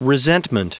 Prononciation du mot resentment en anglais (fichier audio)
Prononciation du mot : resentment